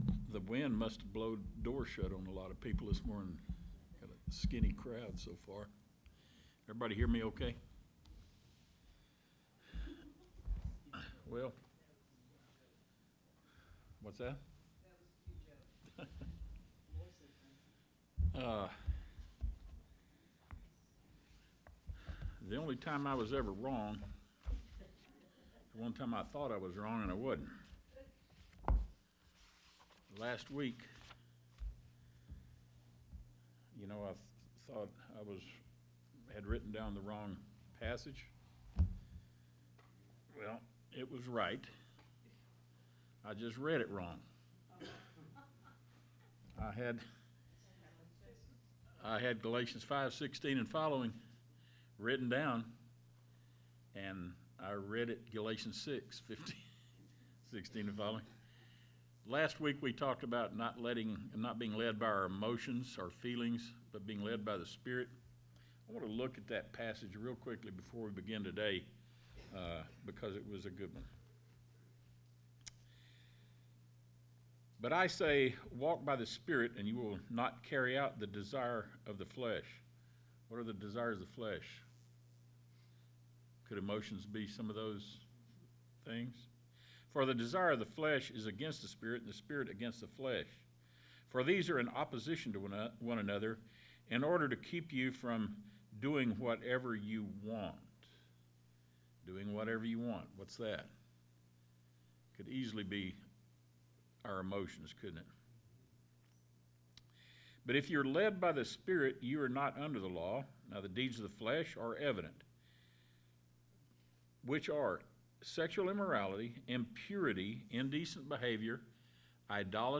Sunday Bible Class